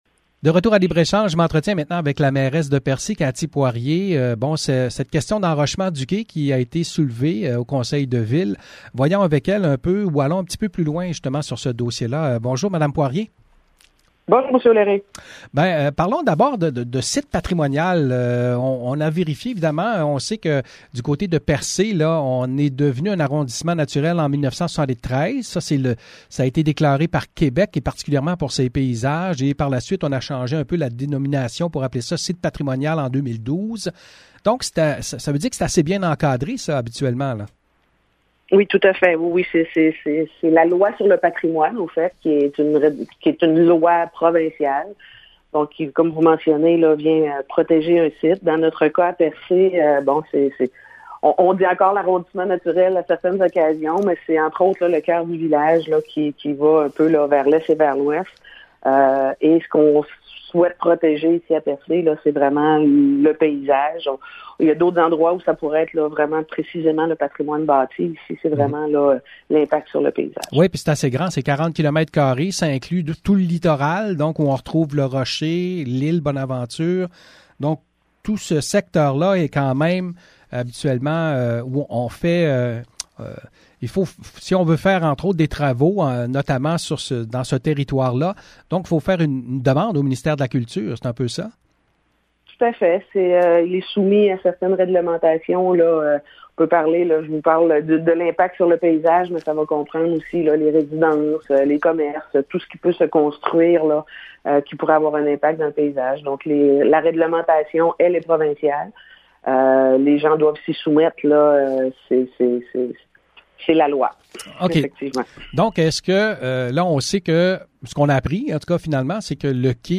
Mercredi à Libre-Échange, nous avons discuté de l’enrochement du quai de Percé qui est critiqué pour avoir été permis dans un site patrimonial protégé pour ses paysages. Vous entendrez un extrait du conseil municipal où une citoyenne est venue s’exprimer sur le sujet.
Extrait du conseil municipal de Percé, courtoisie de la Télé communautaire de Grande-Rivière:
Entrevue avec la mairesse, Cathy Poirier: